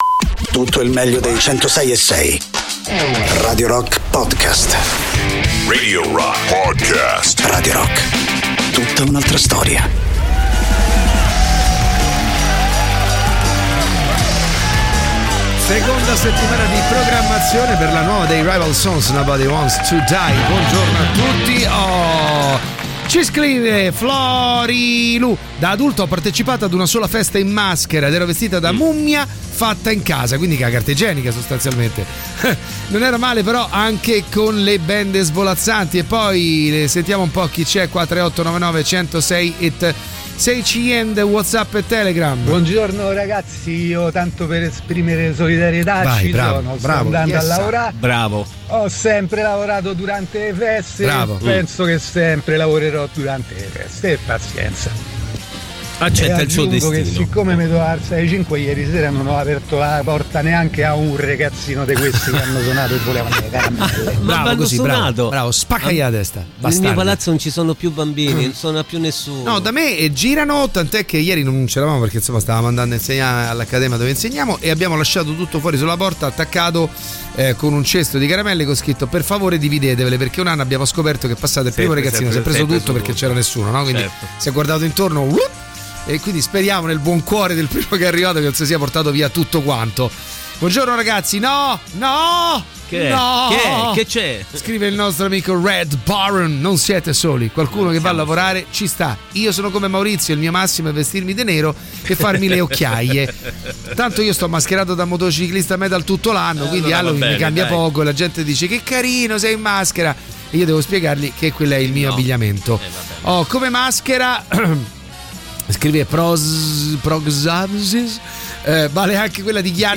in diretta dal lunedì al venerdì dalle 6 alle 10 sui 106.6 di Radio Rock.